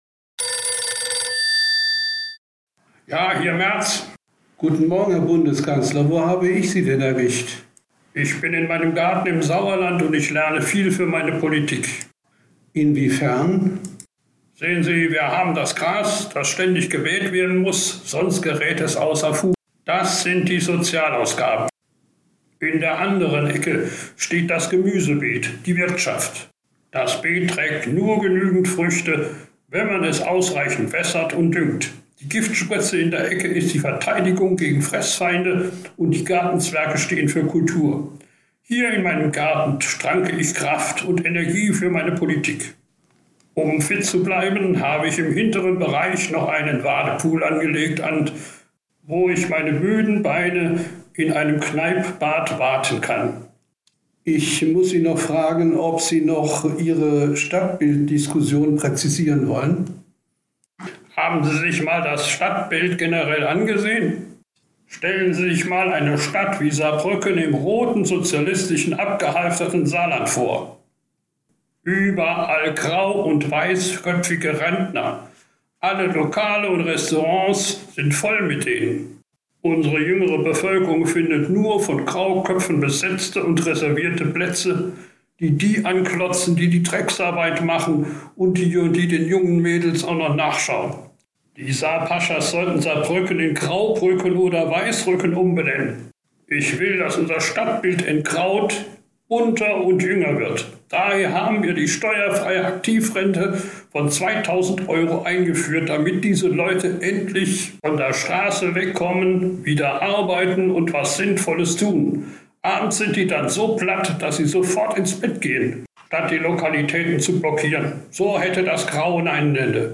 Merz Interview- Garten und Stadtbild